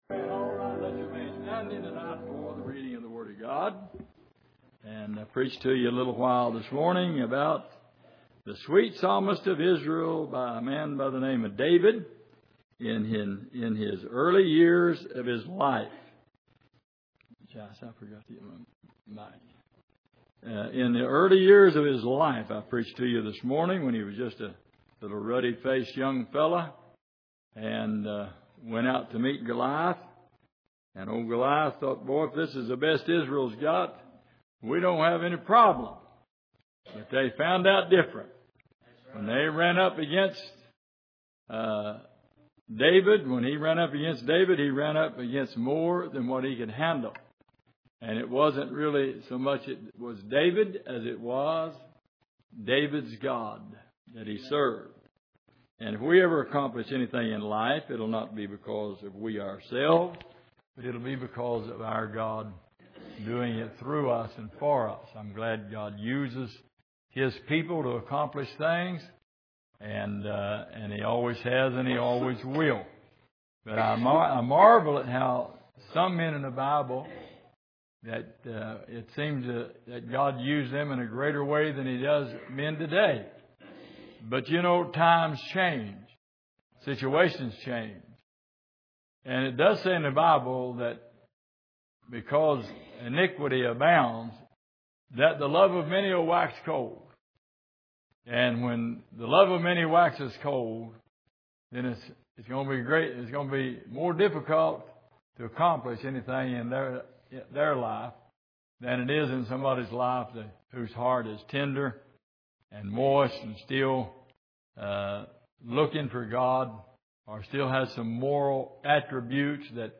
Passage: Psalm 25:1-8 Service: Sunday Evening